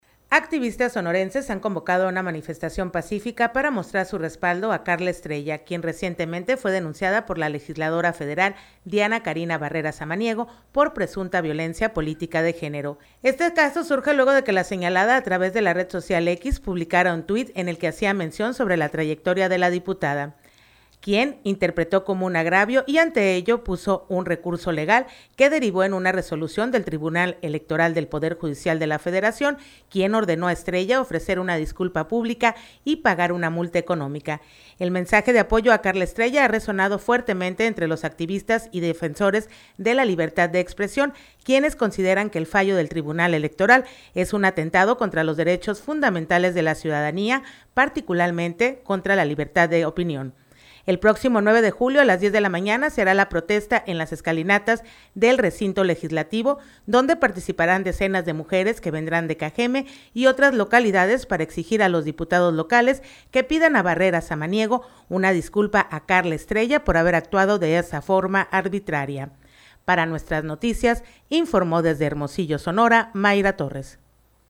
Conductora noticias.